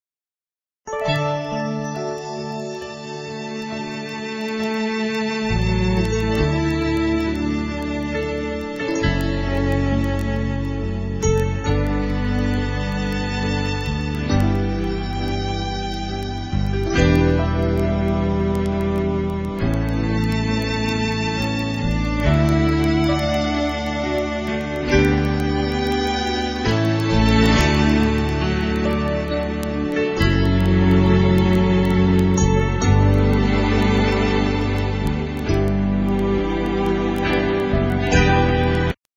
4 tracks WITH GUIDE VOCALS